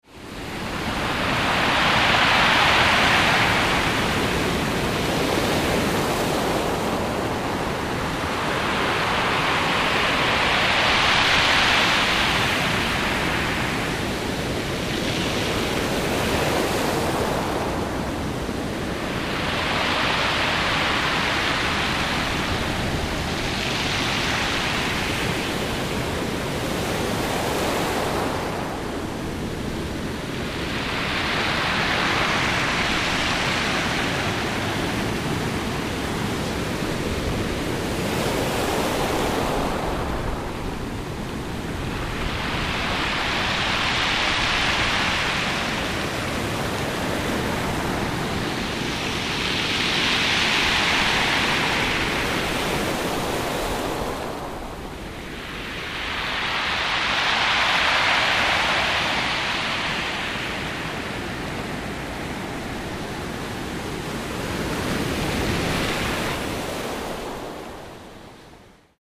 binaural recording of the surf at the waterline